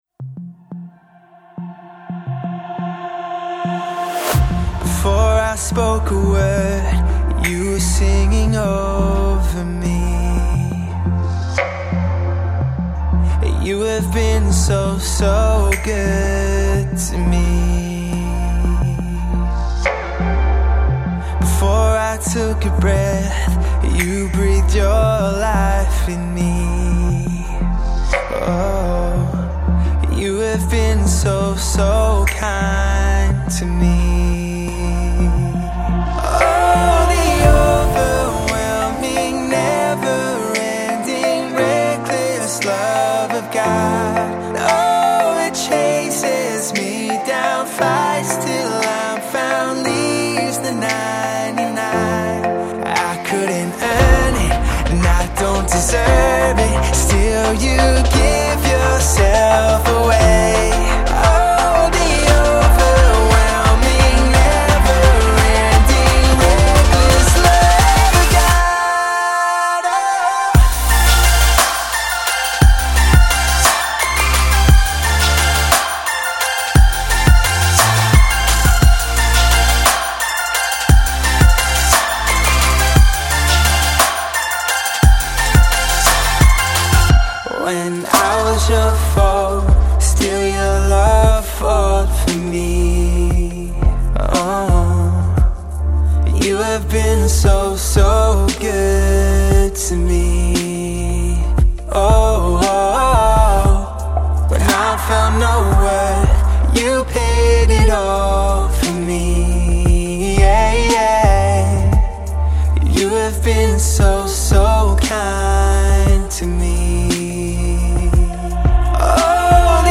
Christian Electronic trio